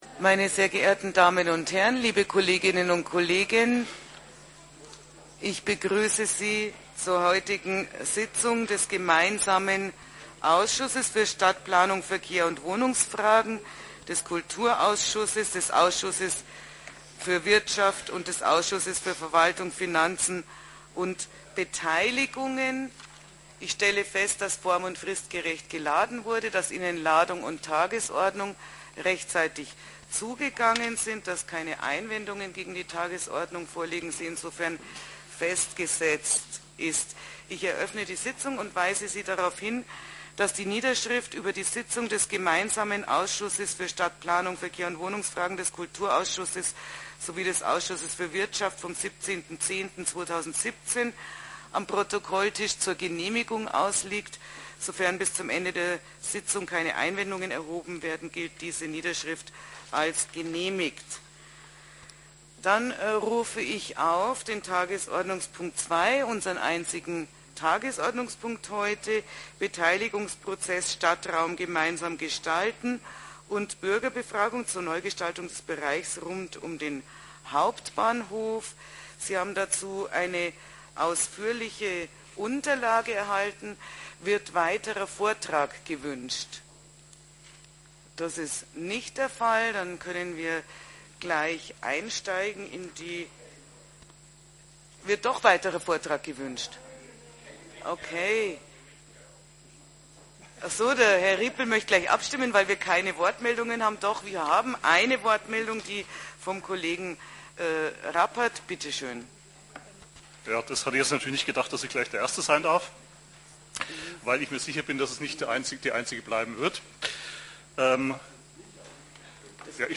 Am Ende diskutierten die Stadträtinnen und Stadträte am Mittwoch dann aber doch fast zweieinhalb Stunden über die Schlussfolgerungen aus der Bürgerbefragung zur Neugestaltung des Areals zwischen Bahnhof und Maxstraße, die in die drei Großprojekte Busbahnhof, Stadtbahntrasse und eben das Ewigkeitsthema RKK münden sollen.